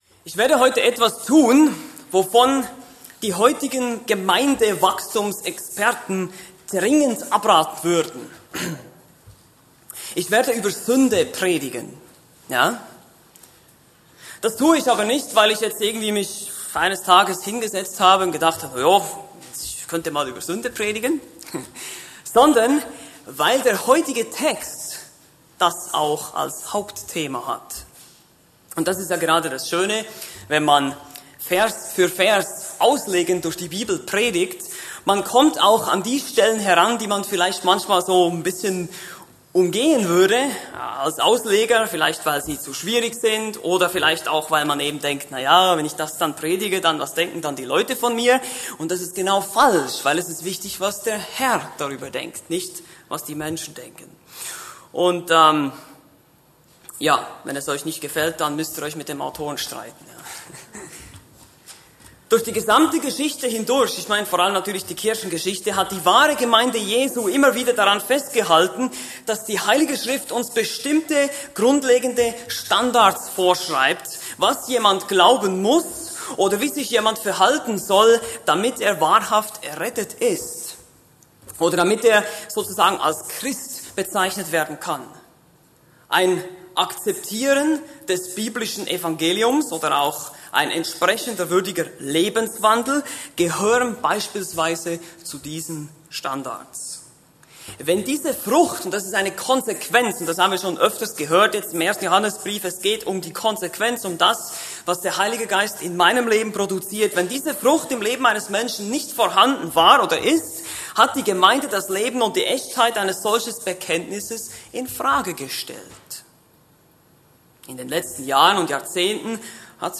Eine predigt aus der serie "Markus."